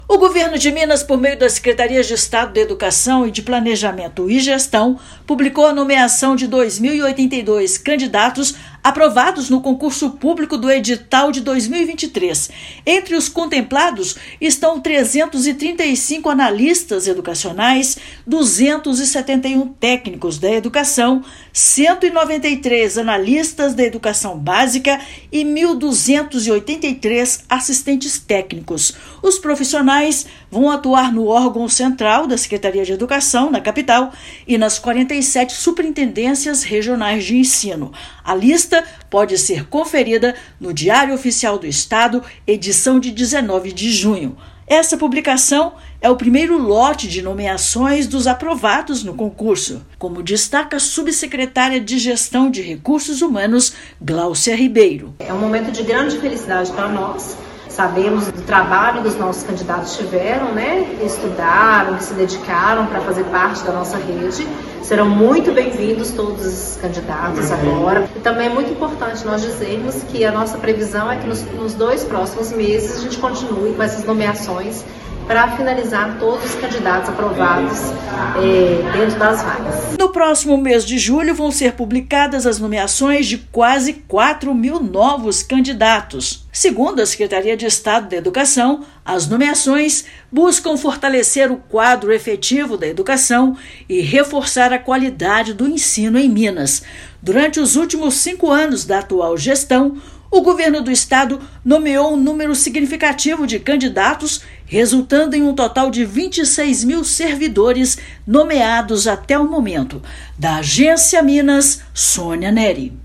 Servidores irão atuar no Órgão Central da Secretaria de Estado de Educação (SEE/MG), em Belo Horizonte, nas 47 Superintendências Regionais de Ensino e nas Escolas Estaduais. Ouça matéria de rádio.